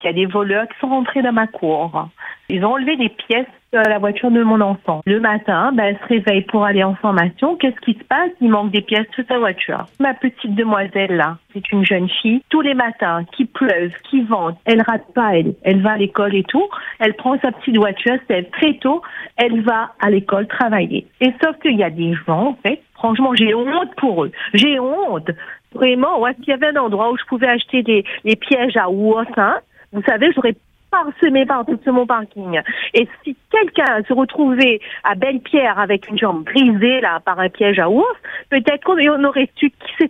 La colère et l’incompréhension dominent dans la voix de cette maman.